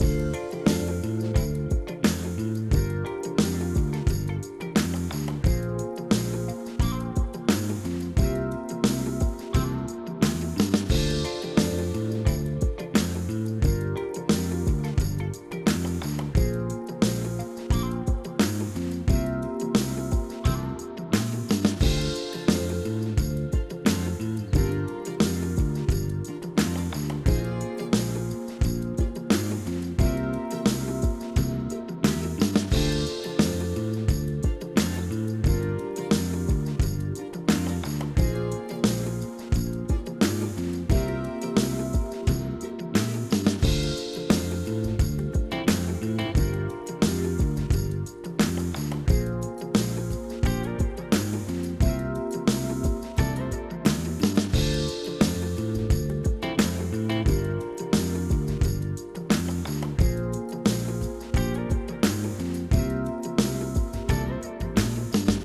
Bass, Drums und Gitarren sind aus dem Plugin. Noch etwas mit Hall verschönert und die Drums mit einem Beat gelayert, den ich in Tonalic importiert habe, damit der Drummer mit dem Beat gemeinsam spielt.